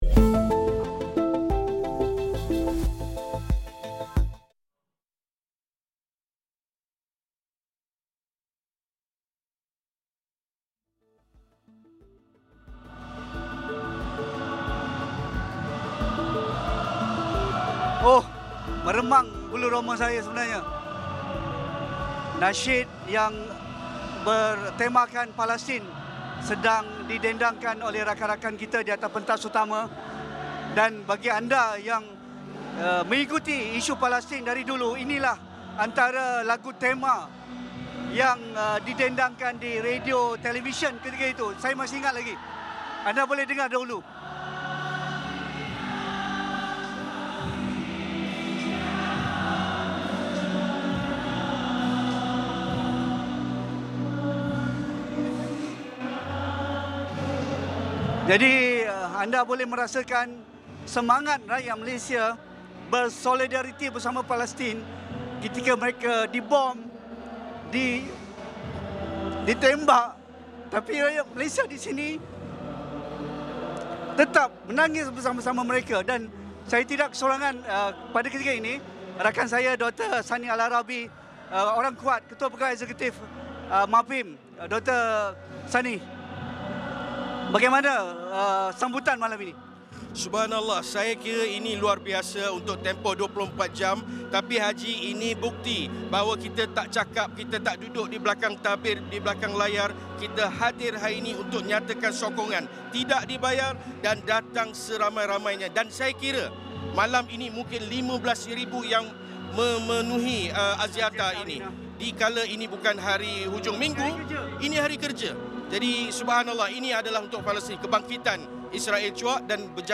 Ikuti Siaran Luar Astro AWANI bersempena Perhimpunan Malaysia Bersama Palestin di Stadium Axiata Arena, Bukit Jalil bermula 7.45 malam ini.